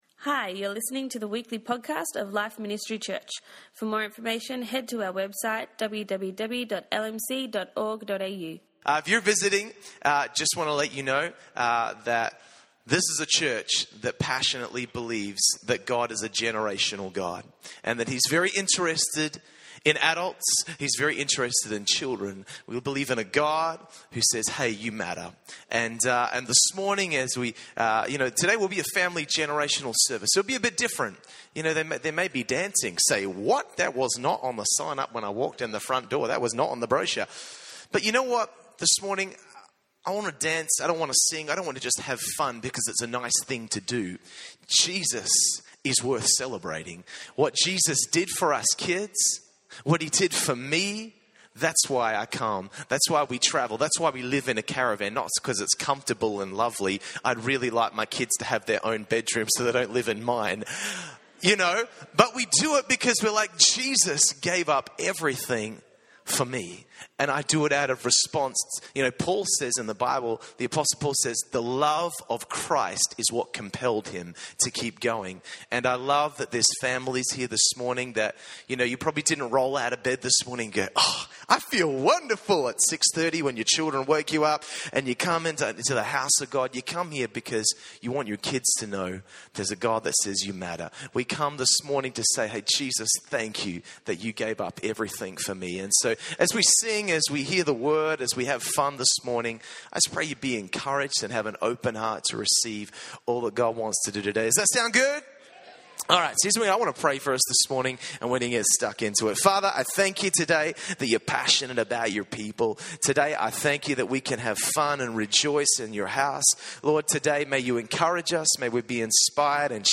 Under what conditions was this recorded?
2016 Family Service